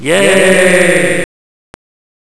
SHTGUN9C.WAV